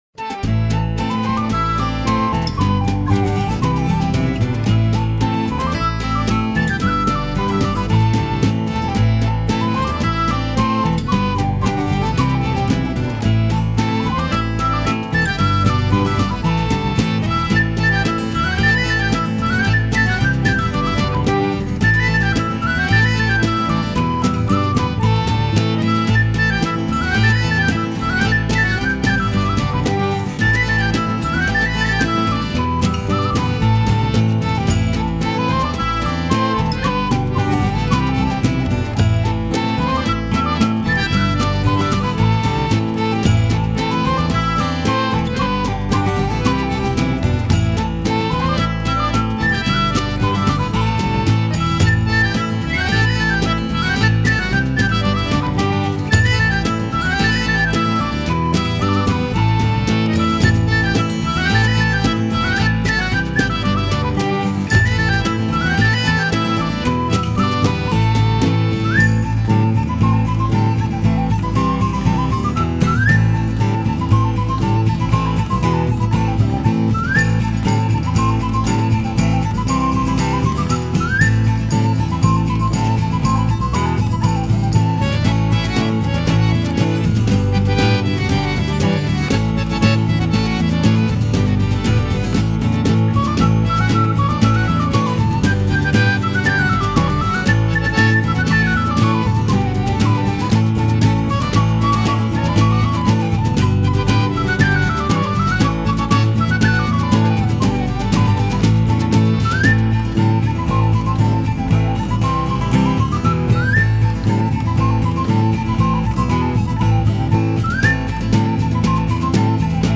Her performances on double Bowed Psaltery are of the highest technical caliber and her passion to bring the Bowed Psaltery to the public eye through educational workshops and performance remains unmatched on the Celtic stage.
New-York-Celtic-Group-1-Battle-of-Aughrim-Cattle-in-the-Corn.mp3